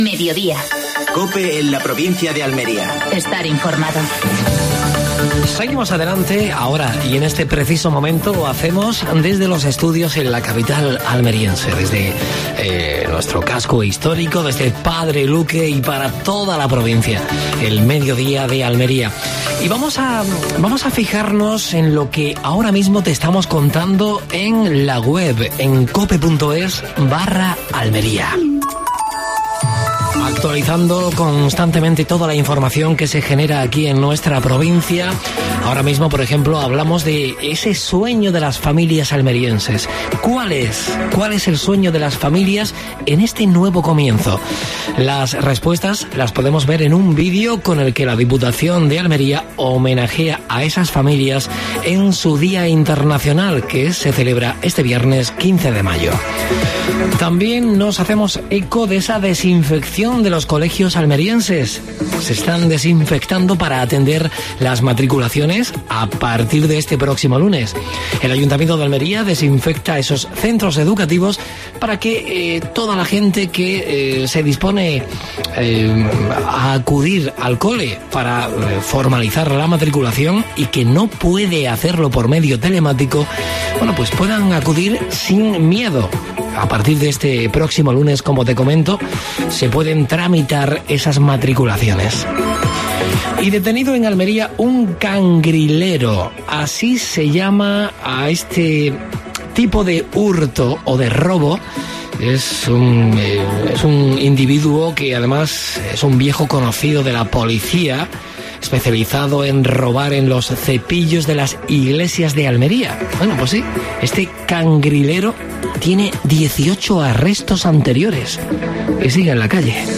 AUDIO: Entrevista al concejal de Promoción de la ciudad, Carlos Sánchez, sobre reapertura de espacios museísticos y situación de los comerciantes...